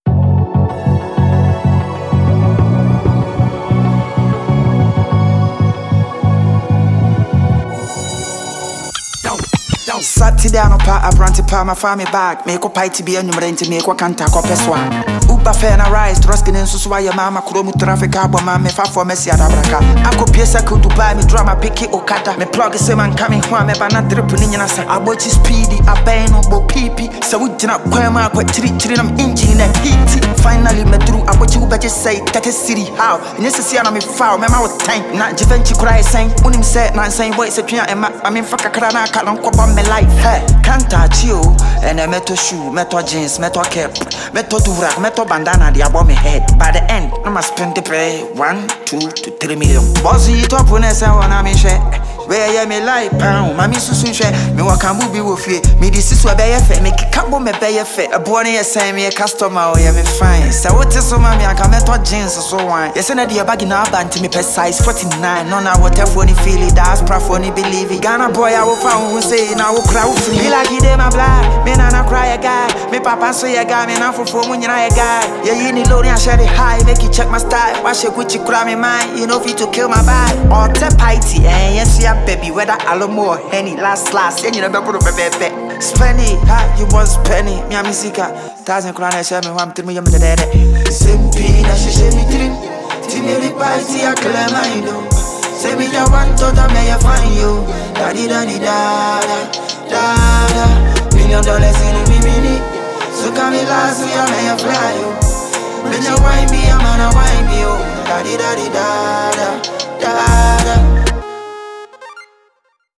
Backed by a gritty beat and sharp delivery